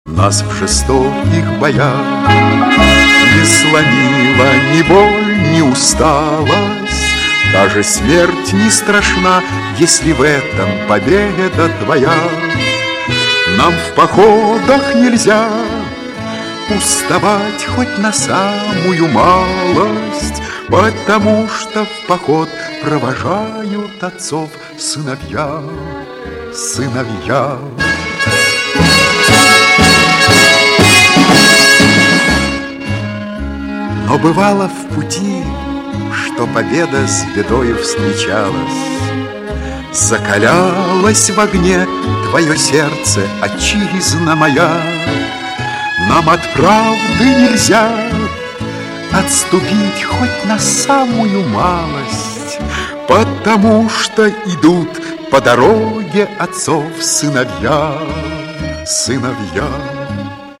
• Качество: 128, Stereo
мужской голос
спокойные